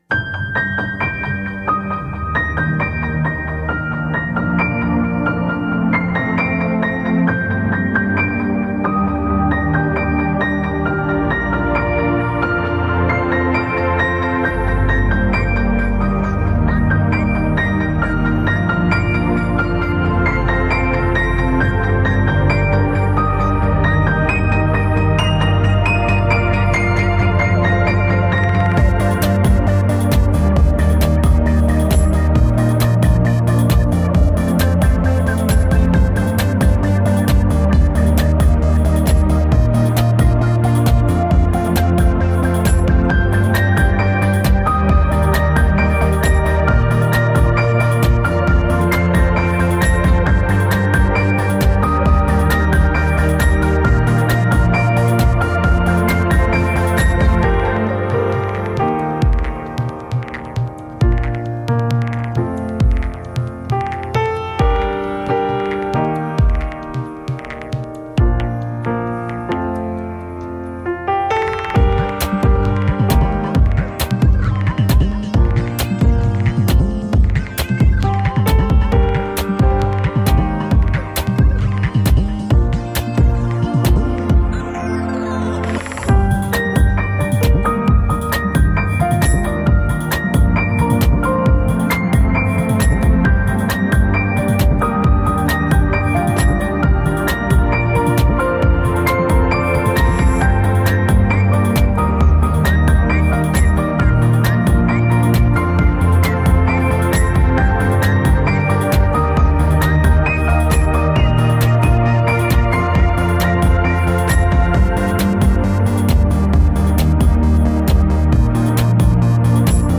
纯音乐版